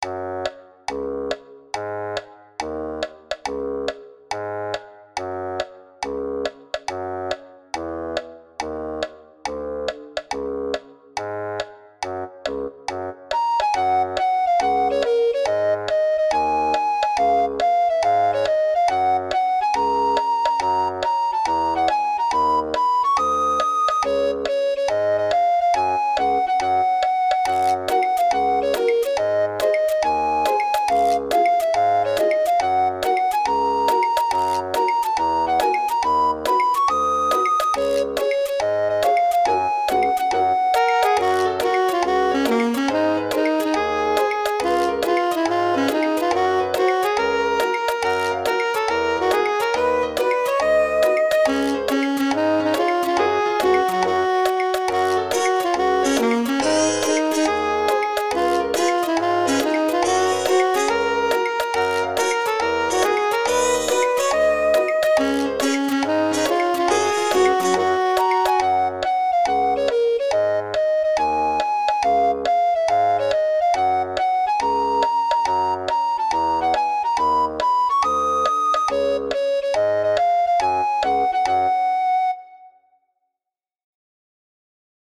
Light and Silly